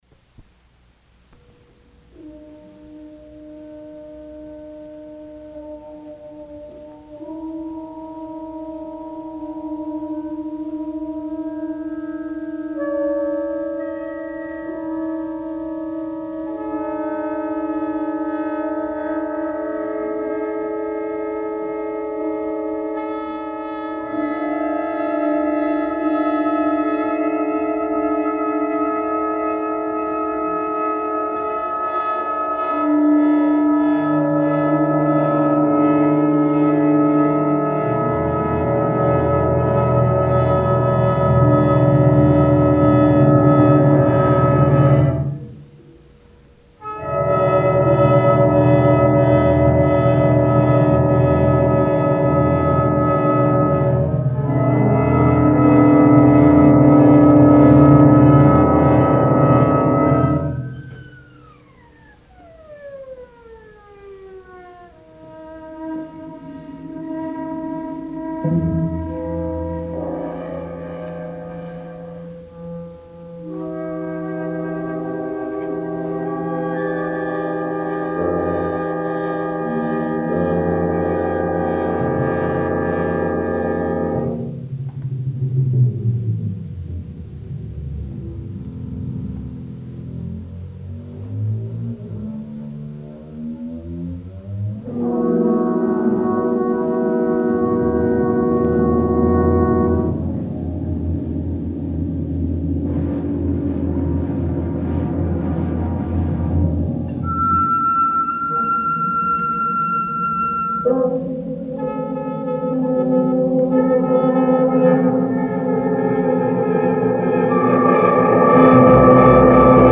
orchestral composition